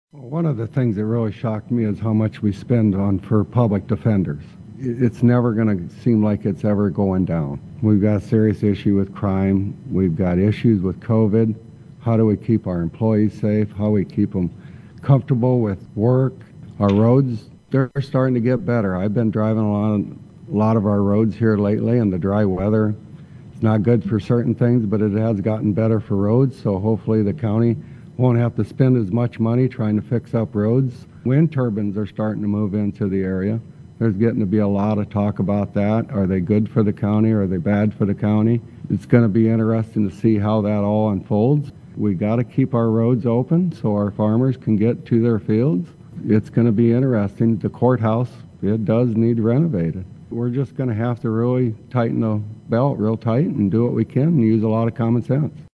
During a candidate forum hosted by the League of Women Voters Pierre and Dakota Radio Group, the candidates agreed lack of revenue, roads and bridges and upgrades needing to be done in the courthouse in Pierre are three major issues for Hughes County right now.